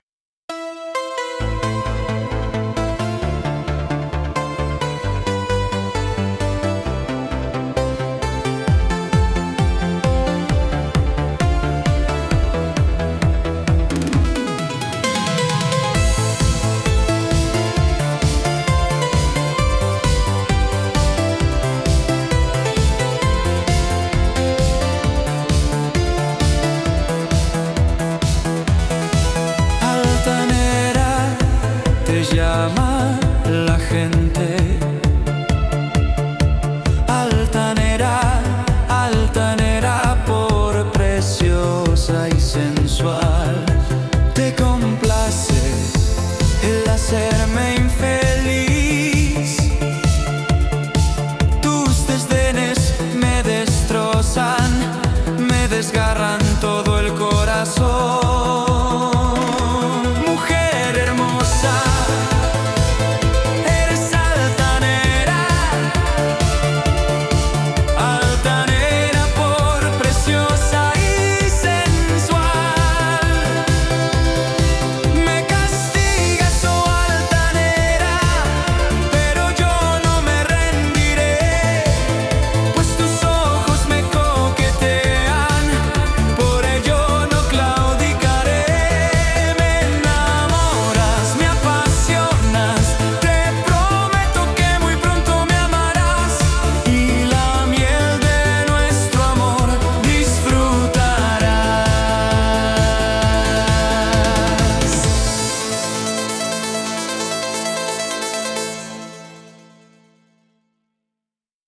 Genre Pop